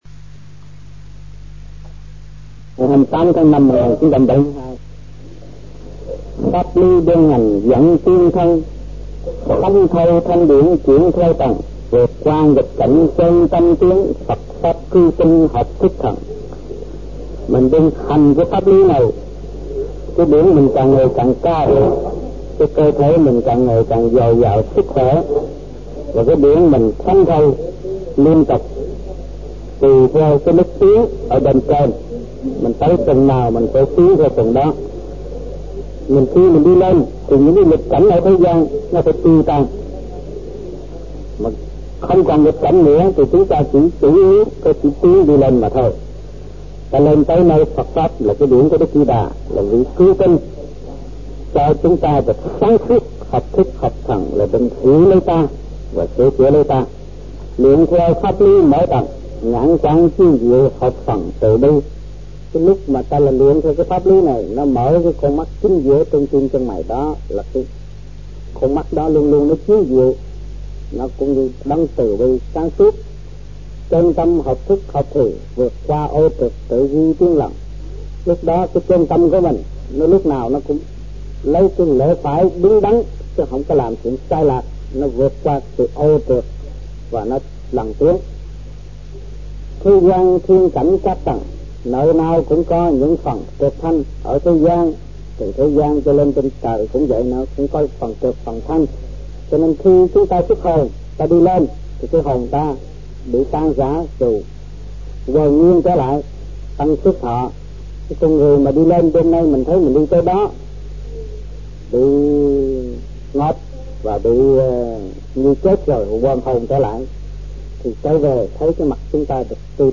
Việt Nam Trong dịp : Sinh hoạt thiền đường >> wide display >> Downloads